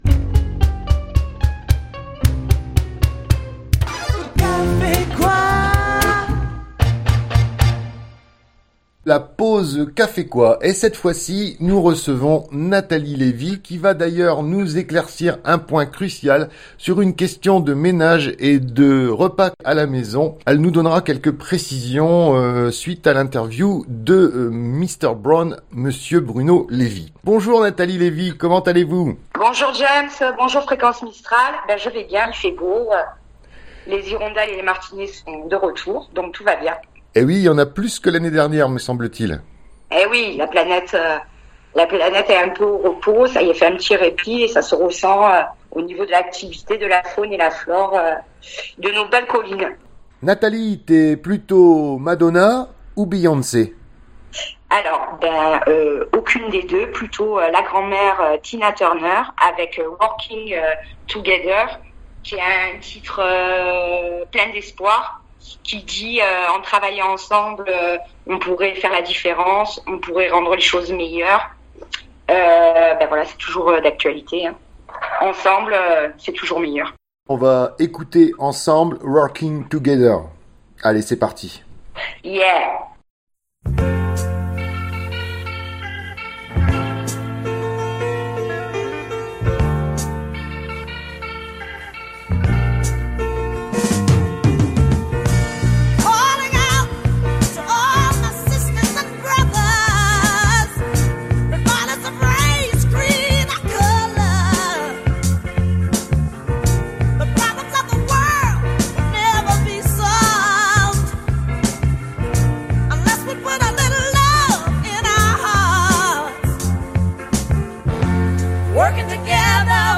Fréquence Mistral et le K'fé Quoi Forcalquier se retrouvent et partagent un rendez-vous " détente " et musical durant lequel, à tour de rôle, un membre de l'équipe vous confie ses goûts musicaux que nous vous diffusons dans la foulée.